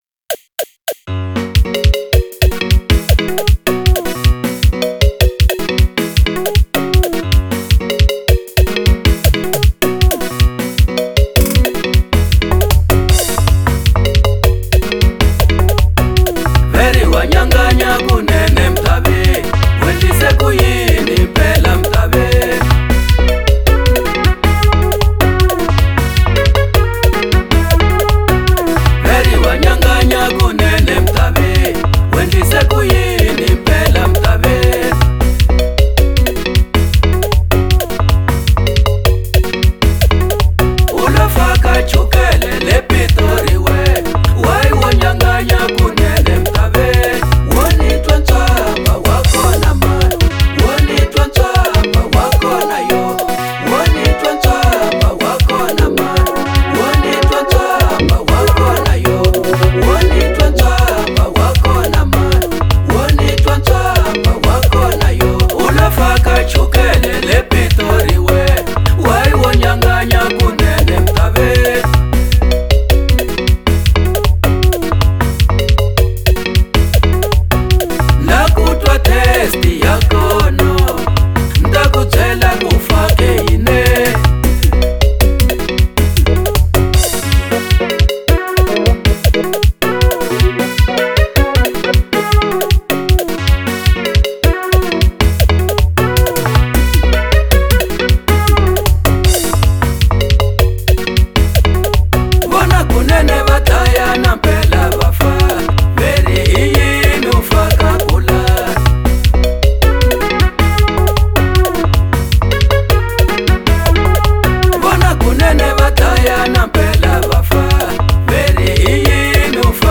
04:43 Genre : Xitsonga Size